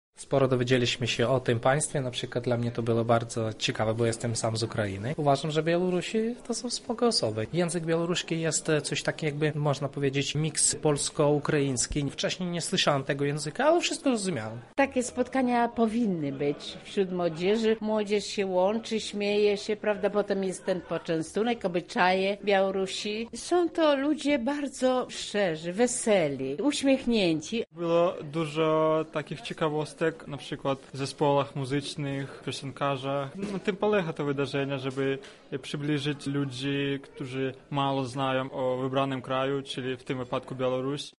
Nasz reporter sprawdził, co zaskoczyło uczestników wydarzenia: